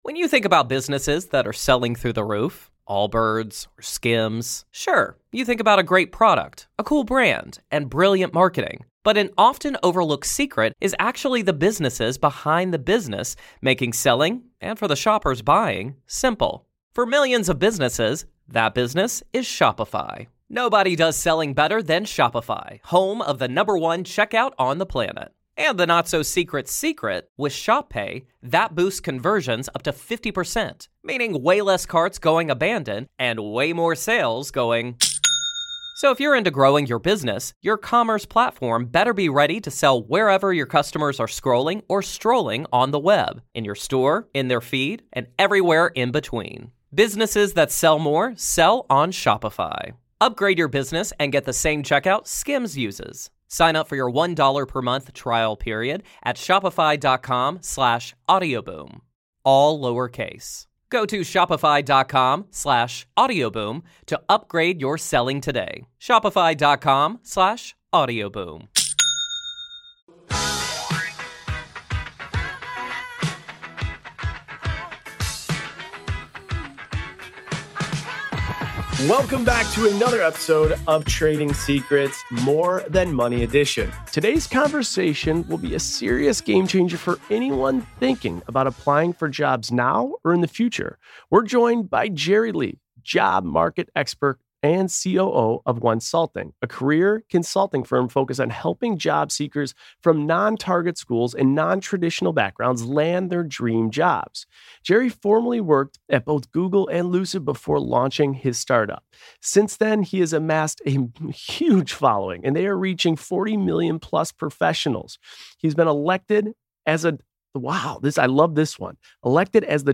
Host: Jason Tartick Co-Host